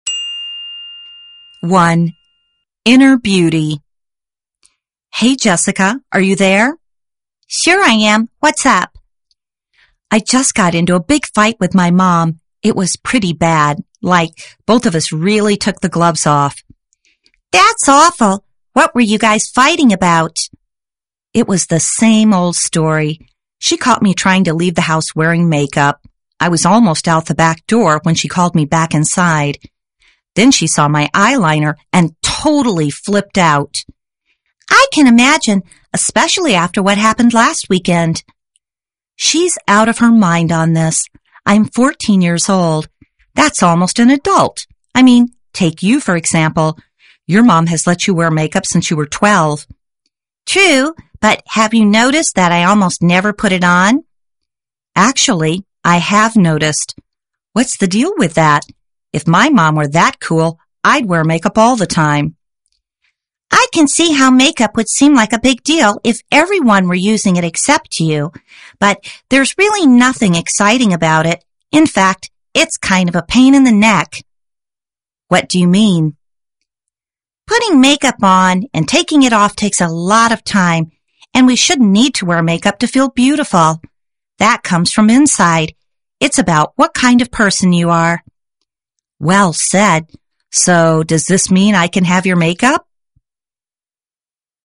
掃描書封QR Code下載「寂天雲」App，即能下載全書音檔，無論何時何地都能輕鬆聽取專業母語老師的正確道地示範發音，訓練您的聽力。